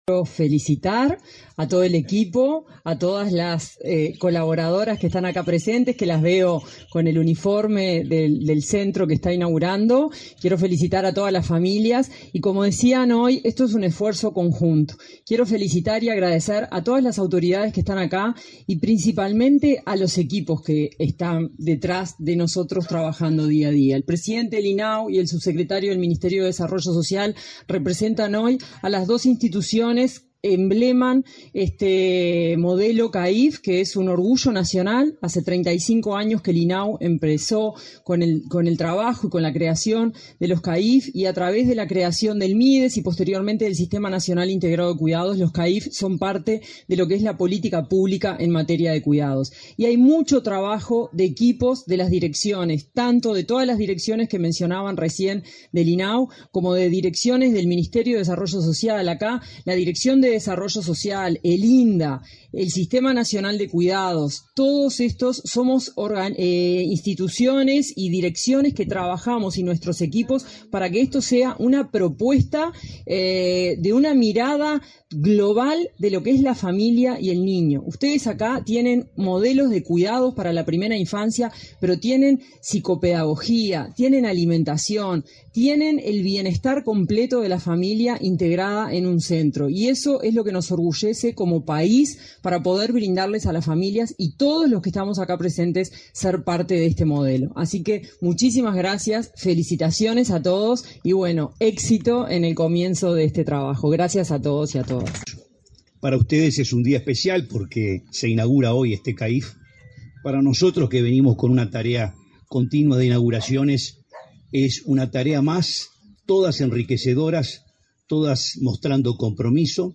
Palabras de autoridades en inauguración de CAIF